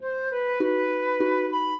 flute-harp
minuet8-9.wav